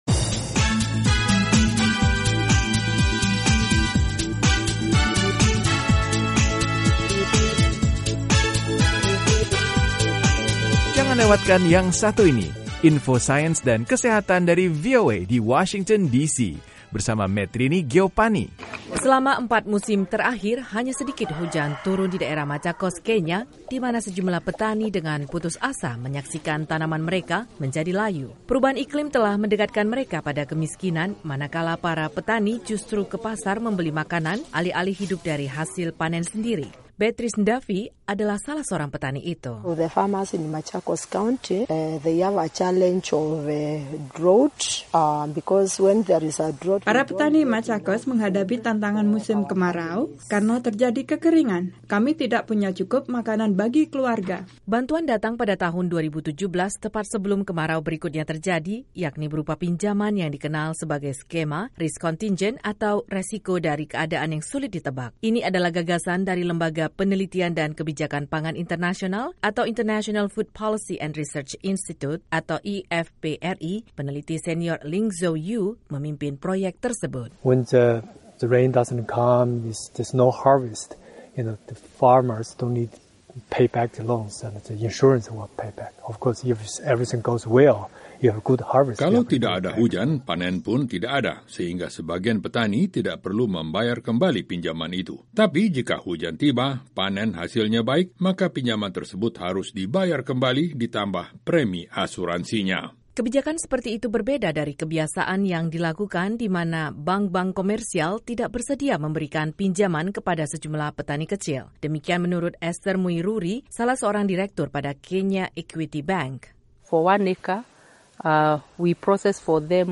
melaporkan dari Machakos.